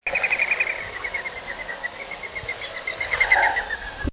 mourning dove
The wing sounds of the Mourning Dove are well known, and are as identifying as his song!